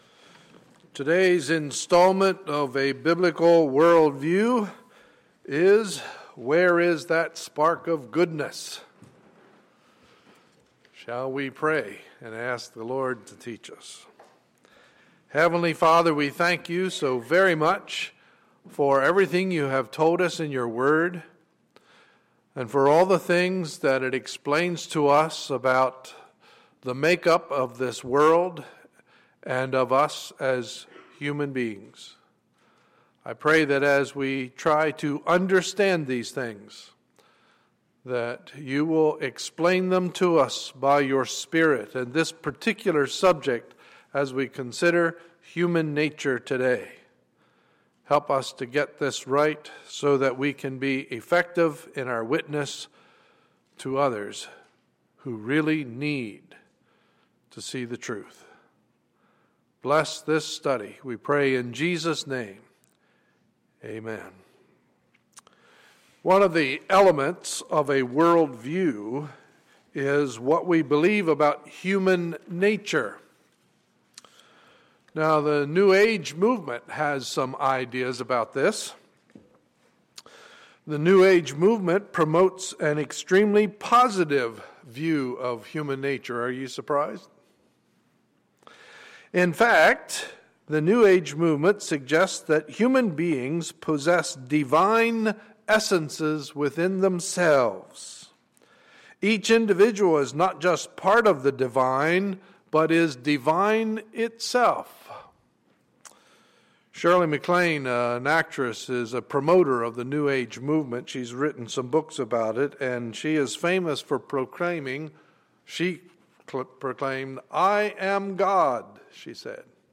Sunday, February 16, 2014 – Morning Service